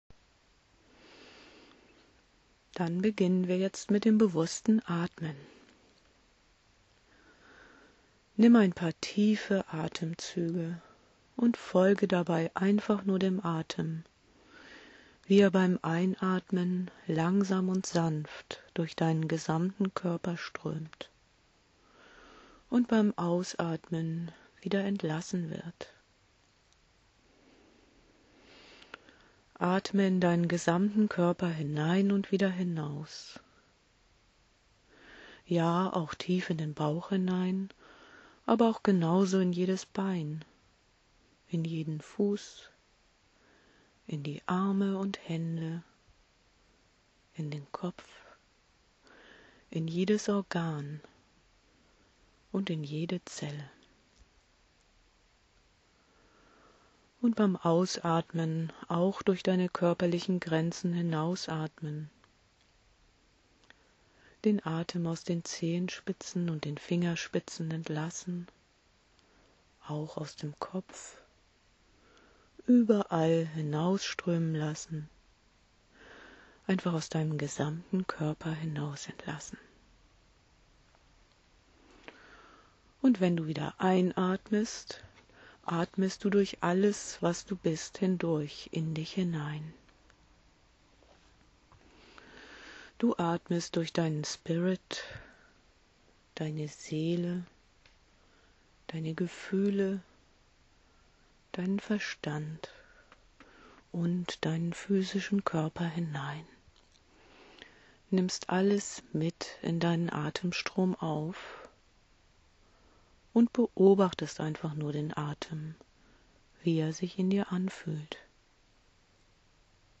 Hier ist eine Atemsequenz, wie sie auch in den Sessions am Anfang vorkommt:
Atmen
atmen.WMA